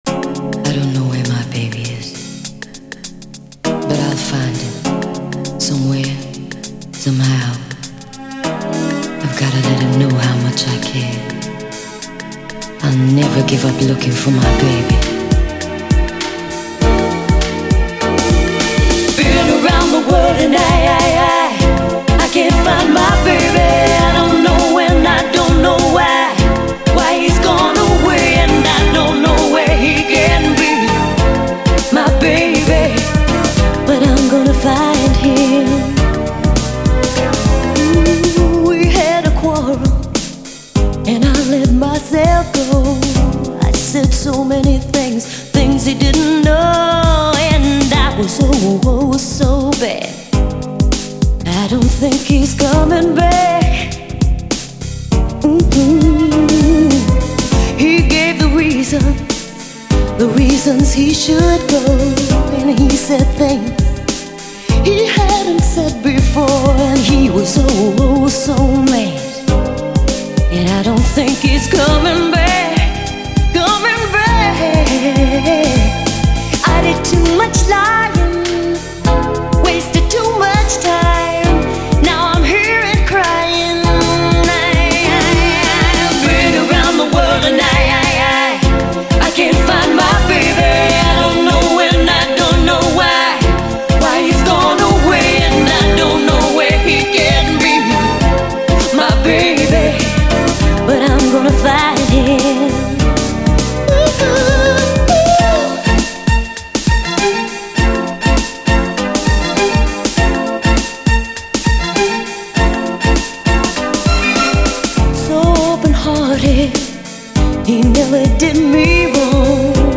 SOUL, 70's～ SOUL, DANCE, 7INCH
流麗＆キャッチー＆ソウルフルな大名曲！